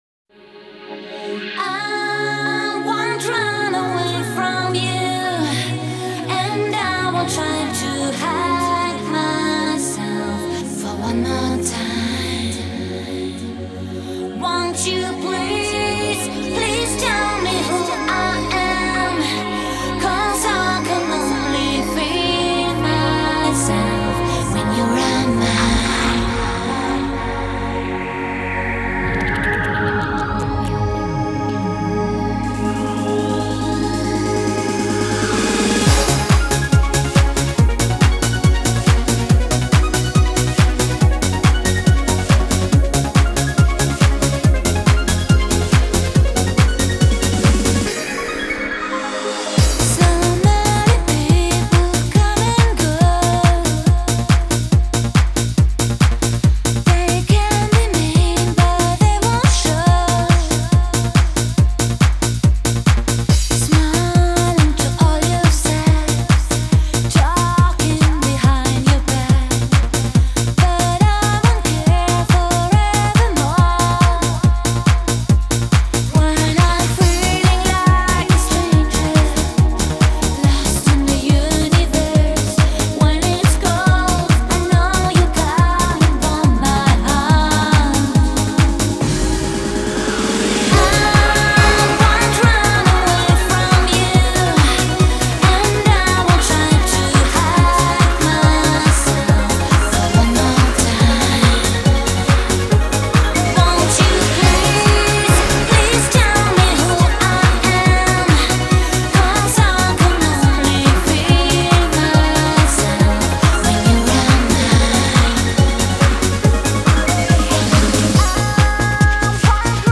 Жанры: синти-поп, танцевальная музыка,
евродиско, поп-рок, евродэнс